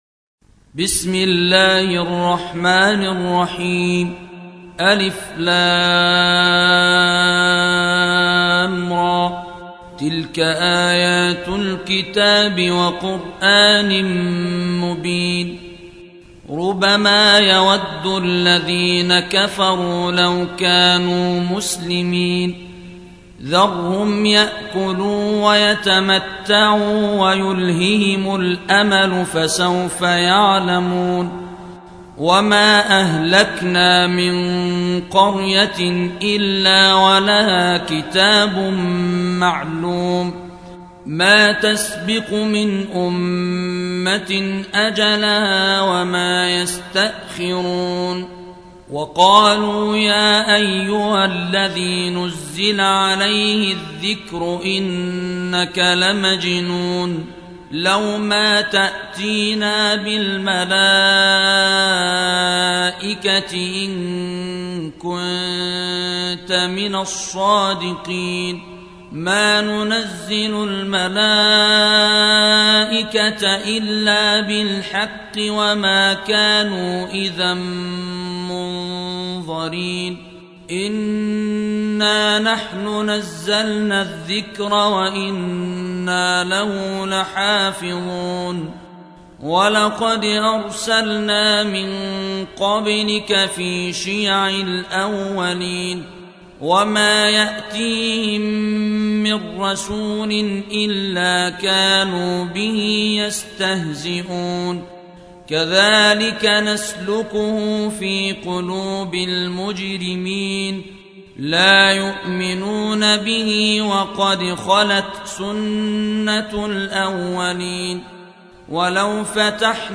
15. سورة الحجر / القارئ